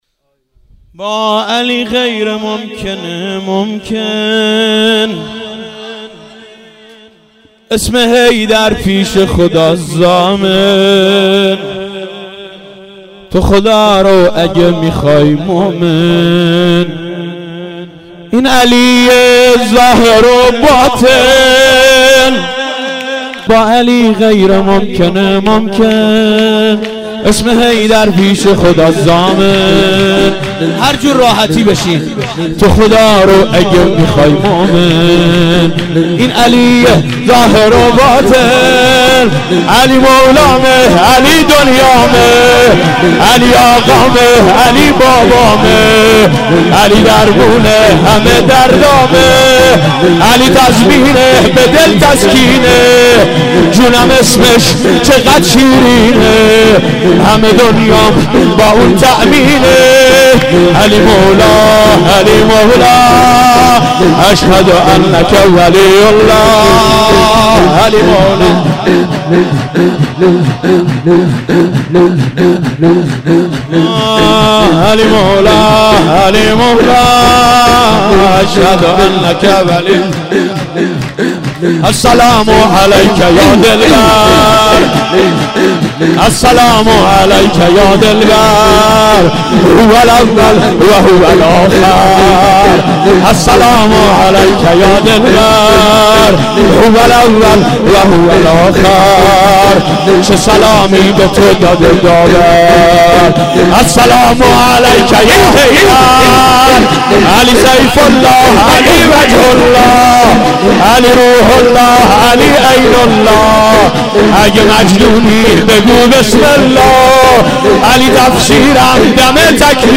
سرود دوم